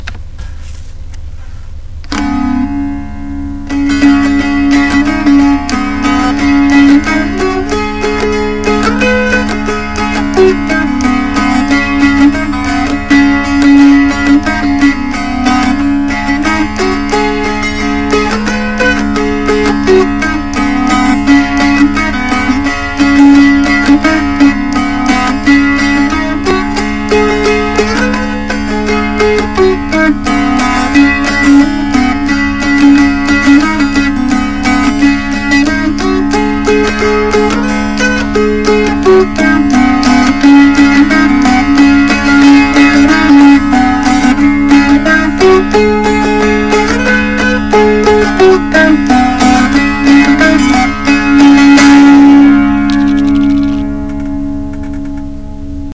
I'd like to think you are only limited by your imagination. click to enlarge More Pictures of the Celtic Dulcimer Listen to the Celtic Dulcimer
ShadyGrovedulcimer.mp3